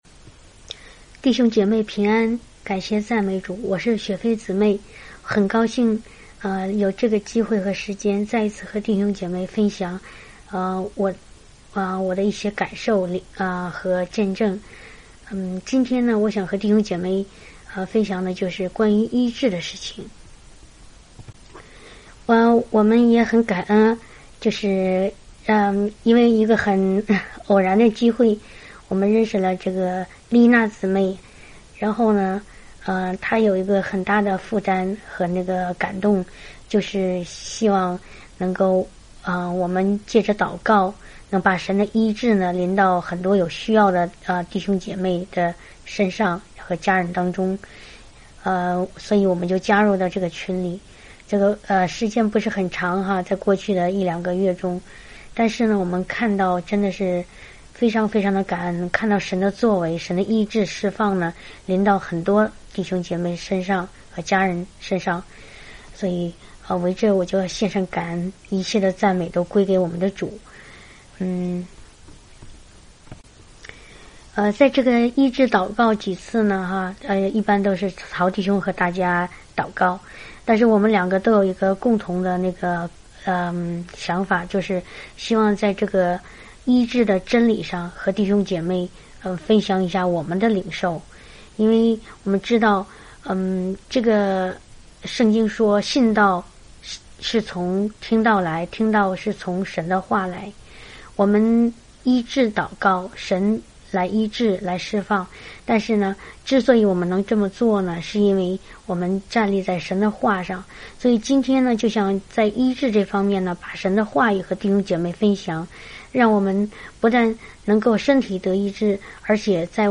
发布于 ： Healing 、 Testimony | 标签： 神的医治 文章导航 赞美、敬拜到荣耀 前一章帖文 分享一首诗歌”被破碎的器皿(奇异恩典)” 后一章帖文 发表回复 您的邮箱地址不会被公开。